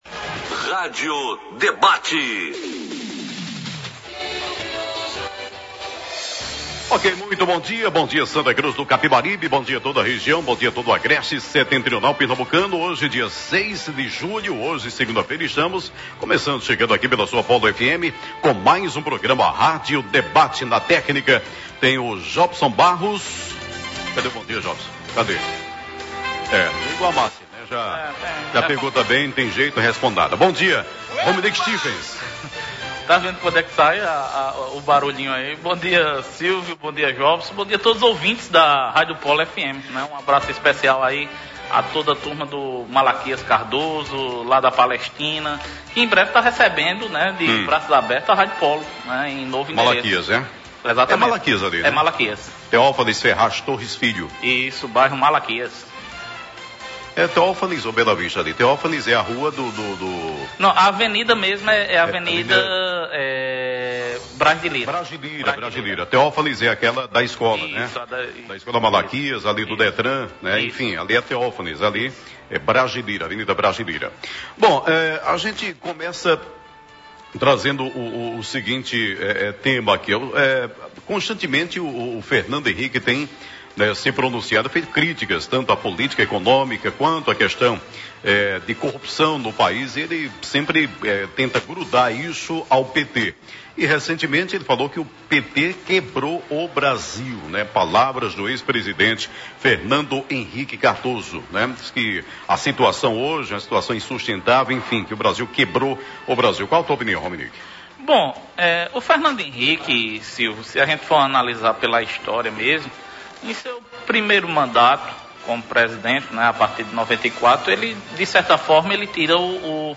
Os debatedores analisaram a atual situação e que rumo seguirá o vereador e pré-candidato a prefeito, Fernando Aragão.